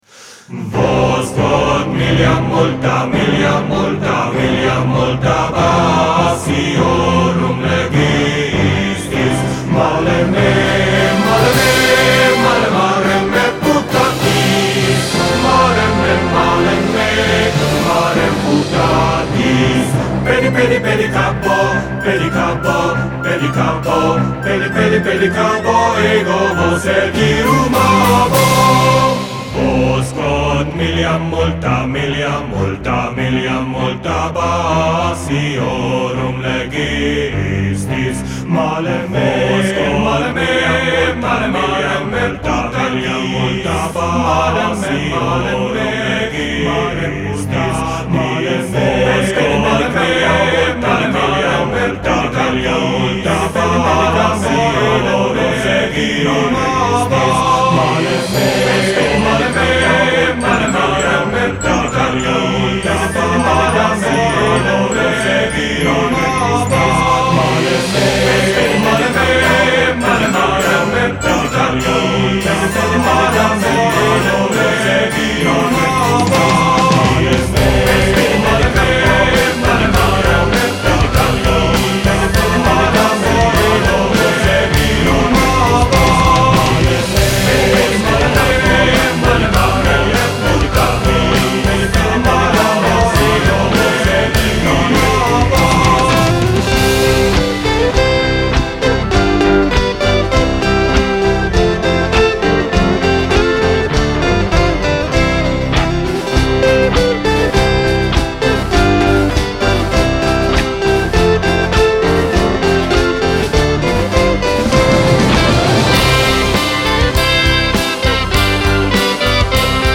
electronic music
(Catullus XVI)   "Round" composition technique 1 of 32 !!!